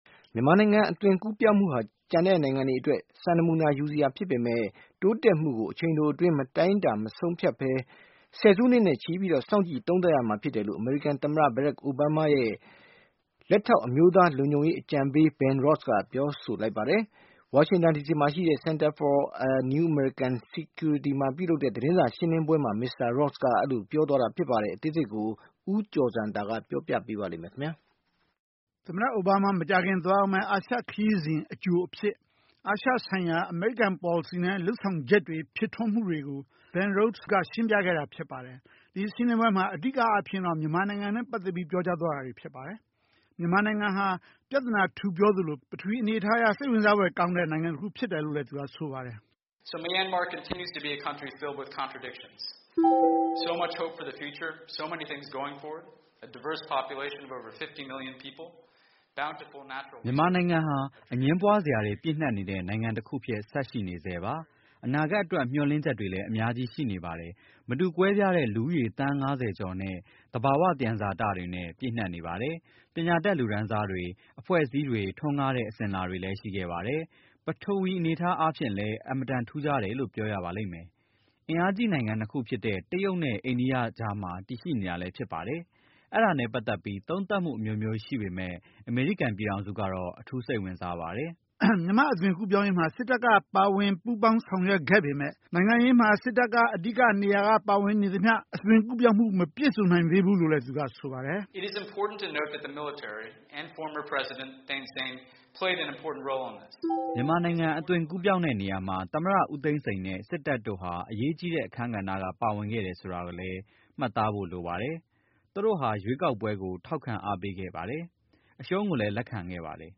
မြန်မာနိုင်ငံ အသွင်ကူးပြောင်းမှုဟာ ကျန်နိုင်ငံတွေအတွက် စံနမူနာယူစရာဖြစ်ပေမဲ့ တိုးတက်မှုကို အချိန်တိုအတွင်း မတိုင်းတာ မဆုံးဖြတ်ဘဲ ဆယ်စုနှစ်နဲ့ချီပြီး စောင့်ကြည့် သုံးသပ်ရမှာ ဖြစ်တယ်လို့ အမေရိကန်သမ္မတ Barack Obama ရဲ့ လက်ထောက် အမျိုးသားလုံခြုံရေးအကြံပေး Ben Rhodes က ပြောကြားလိုက်ပါတယ်။ ဝါရှင်တန်ဒီစီမှာရှိတဲ့ Center for a New American Security မှာ ပြုလုပ်တဲ့ သတင်းစာရှင်းလင်းပွဲမှာ မစ္စတာ Rhodes က ပြောကြားသွားတာပါ။
by ဗွီအိုအေသတင်းဌာန